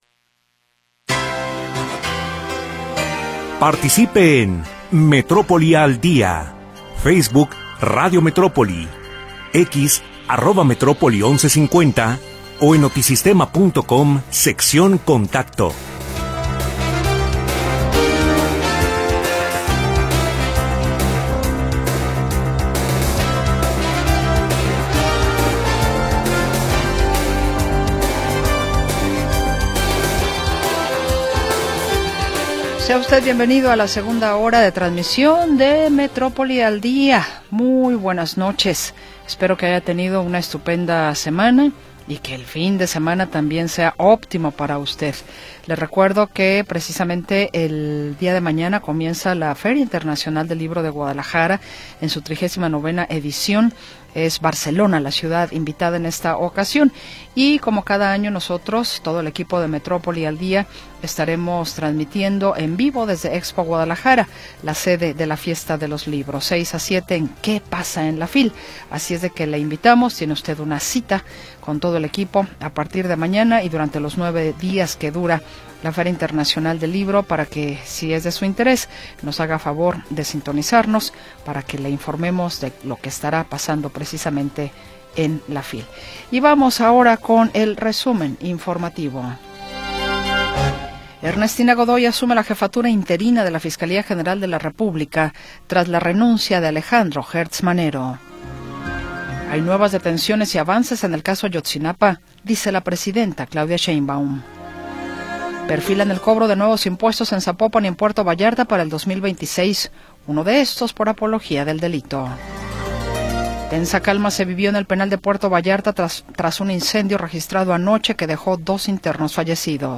Segunda hora del programa transmitido el 28 de Noviembre de 2025.